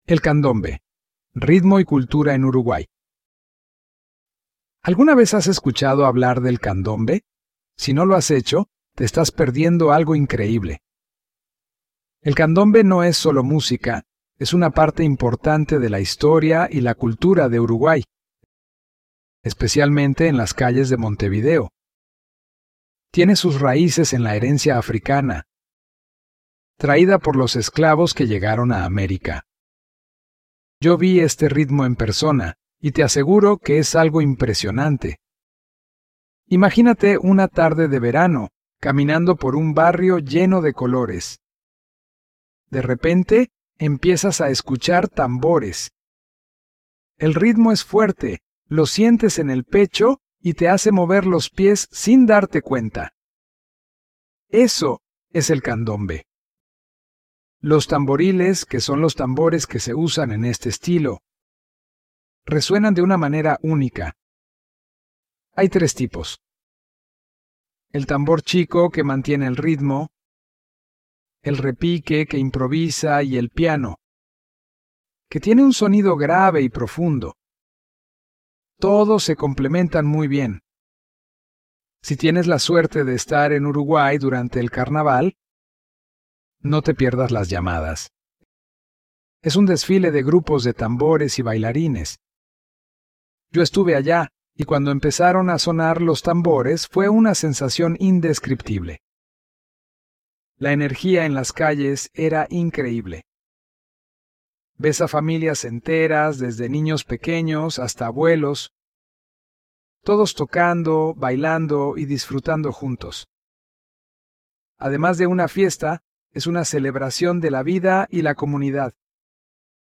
Spanish online reading and listening practice – level B1